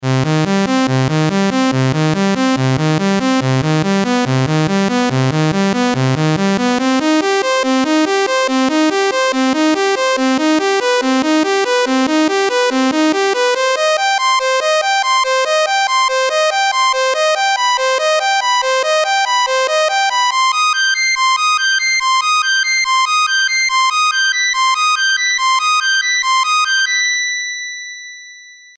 Here’s a side-by-side comparison of Mono Mode (A) and Legato Mode (B)